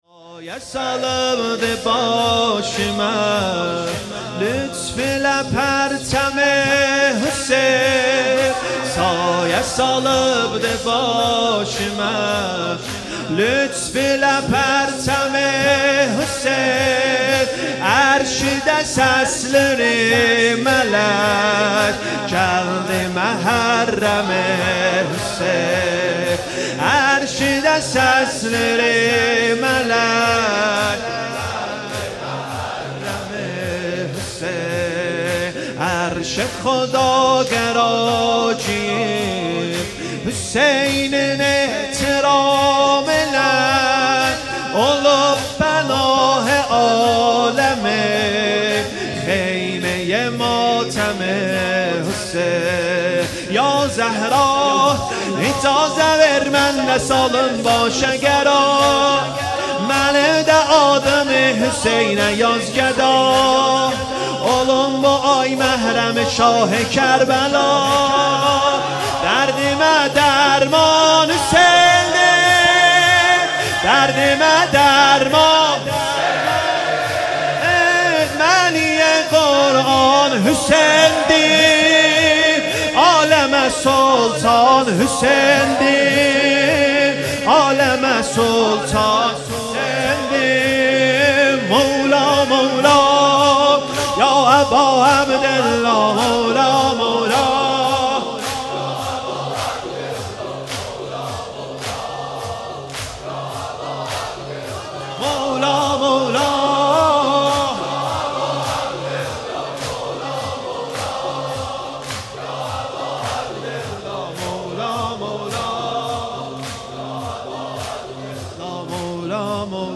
سینه زنی زمینه 📽 شب اول محرم ۱۴۴۵
🕌حسینیه ام الشهدا